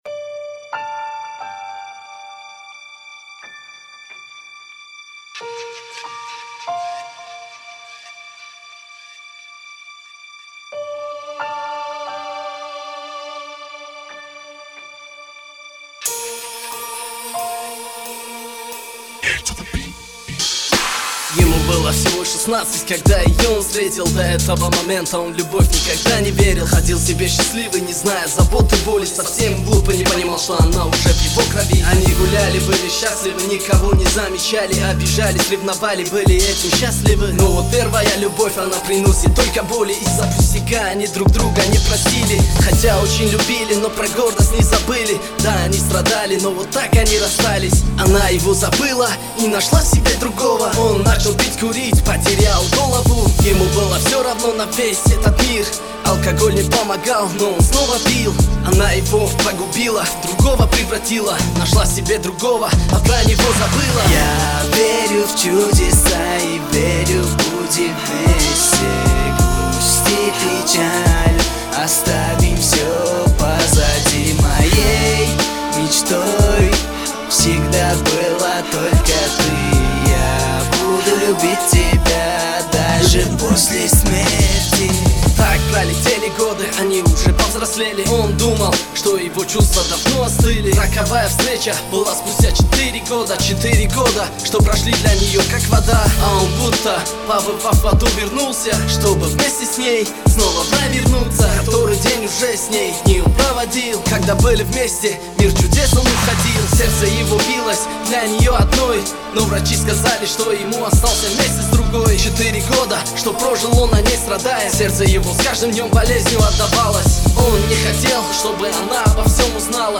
Главная » Каталог mp3 » Рэп / HIP HOP » Tajik Rap